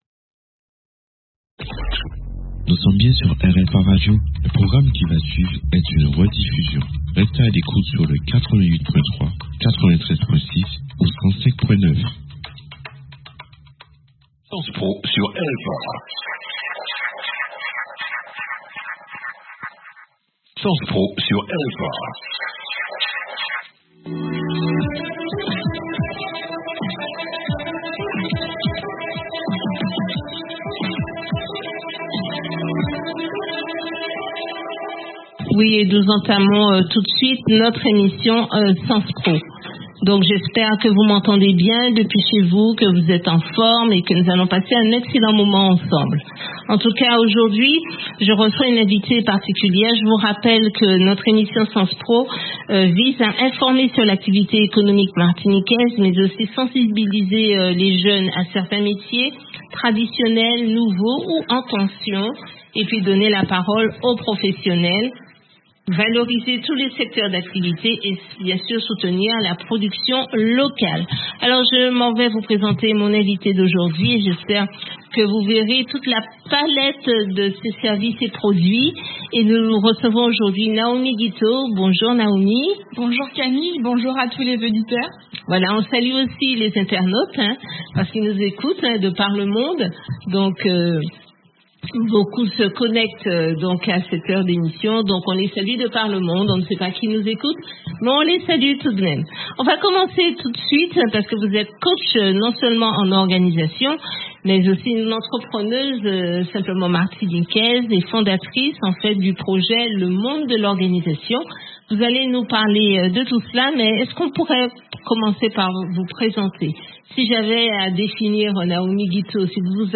Interview blog podcast
J’ai eu l’honneur d’être invitée à prendre le micro sur RFA Martinique pour une émission dédiée à un sujet qui nous touche toutes : la charge mentale, l’organisation et le quotidien des femmes actives.